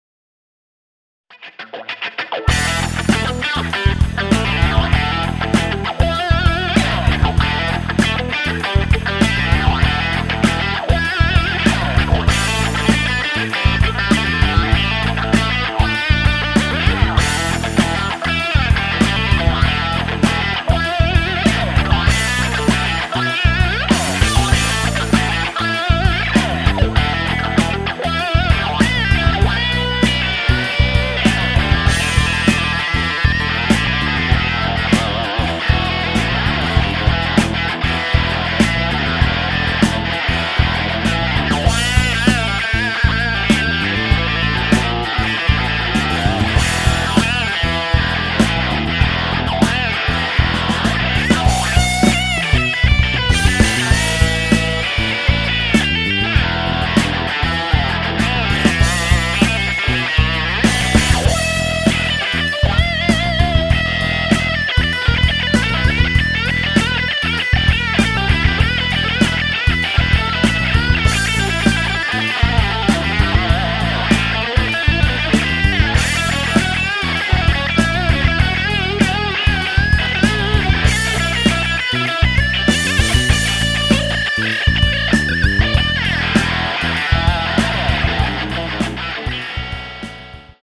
[Blues]